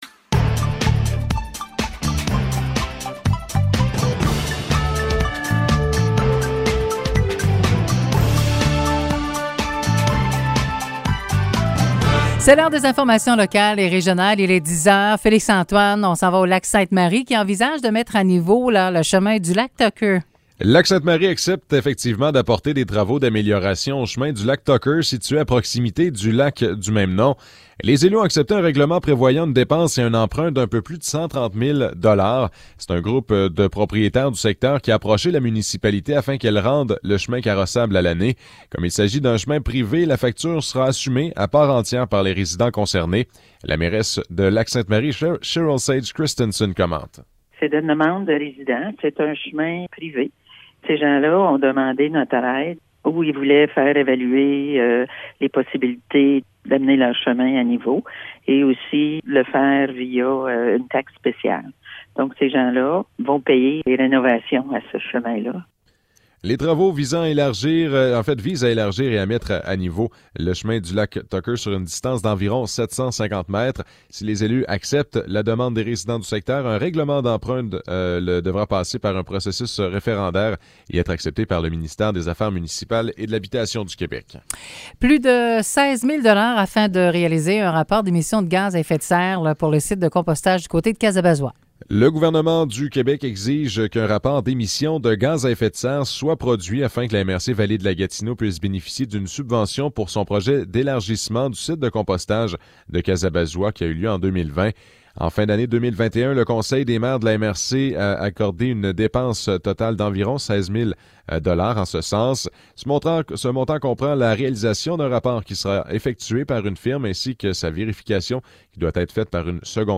Nouvelles locales - 18 janvier 2022 - 10 h